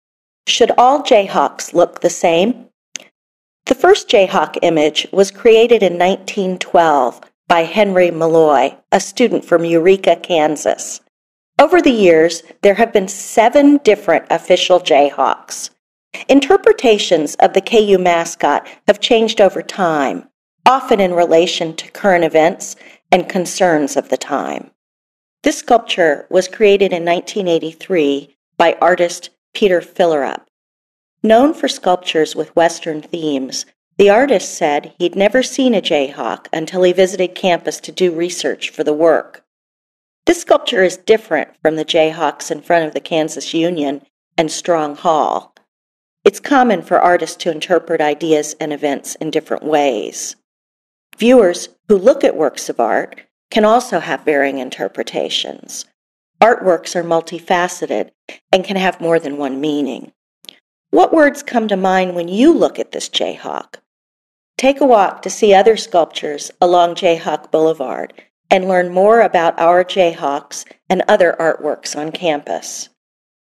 Audio Tour – Ear for Art